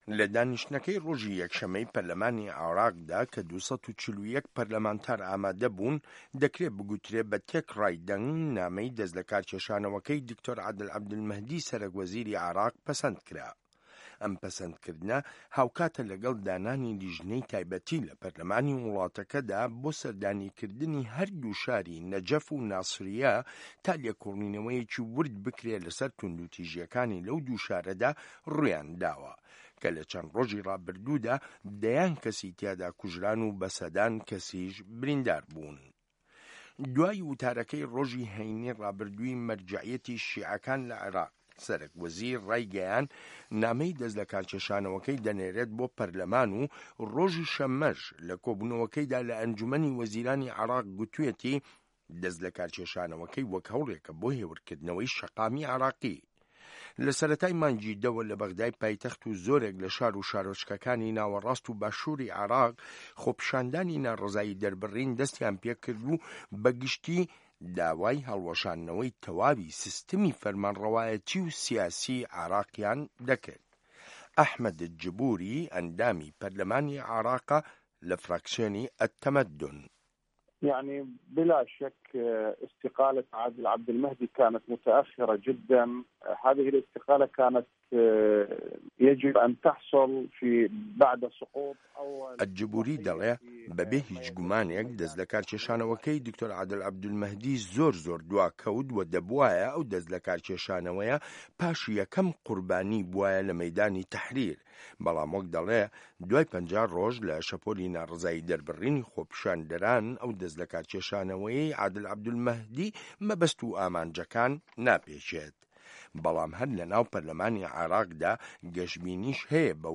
ڕاپۆرت لەسەر بنچینەی لێدوانەکانی موحەمەد ئەلکەربولی و ئەحمەد ئەلجبوری